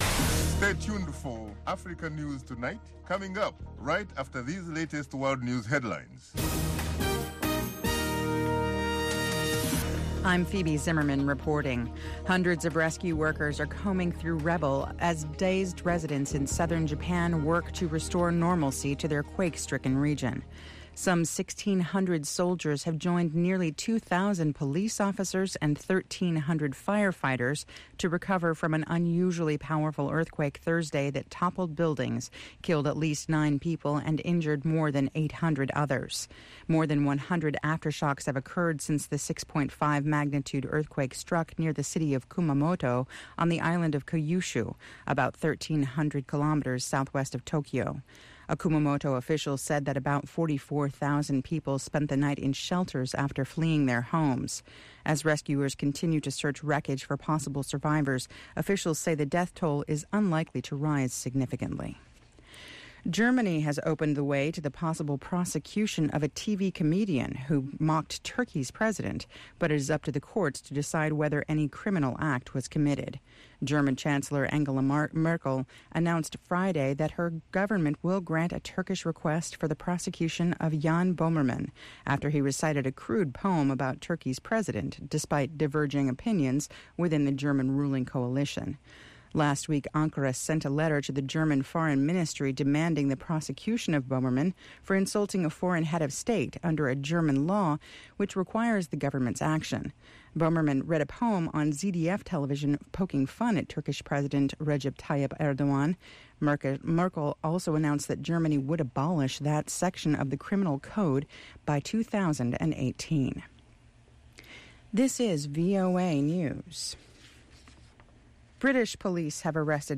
2 Minute Newscast